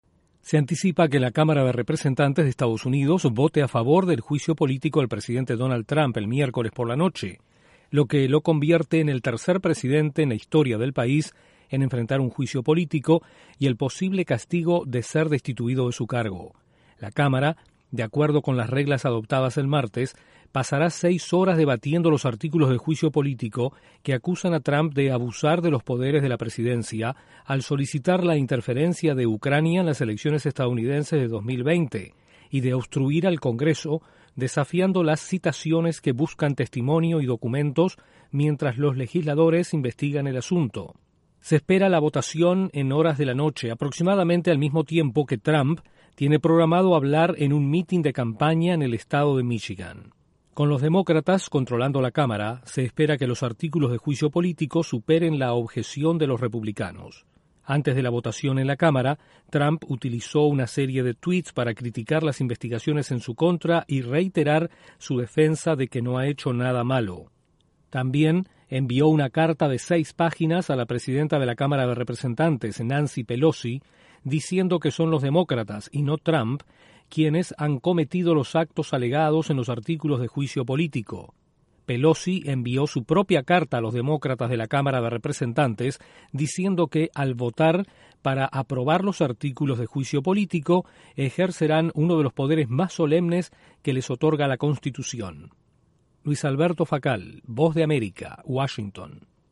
La Cámara de Representantes de EE.UU. vota hoy sobre el juicio político al presidente Donald Trump, mientras el mandatario hablará en un acto de campaña en Michigan. Informa